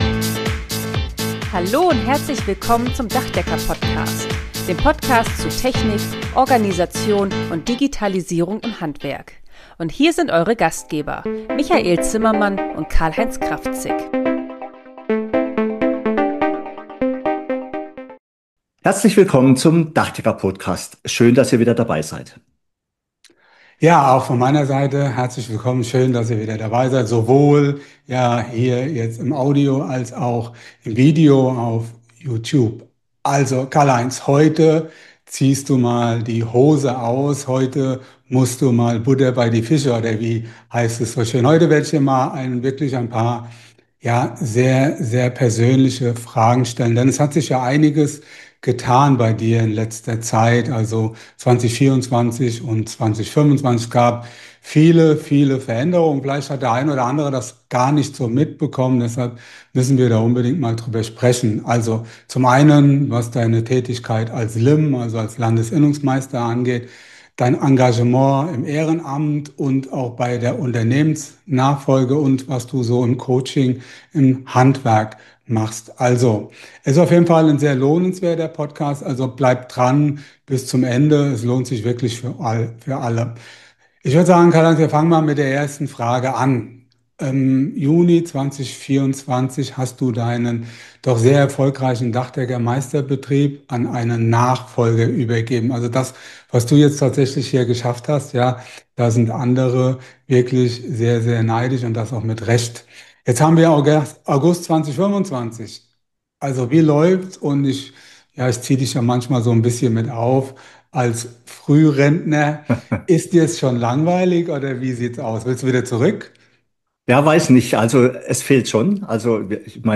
Verbandsarbeit, Nachfolge und Coaching: Ein sehr persönliches Interview